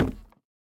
Minecraft Version Minecraft Version latest Latest Release | Latest Snapshot latest / assets / minecraft / sounds / block / chiseled_bookshelf / step1.ogg Compare With Compare With Latest Release | Latest Snapshot
step1.ogg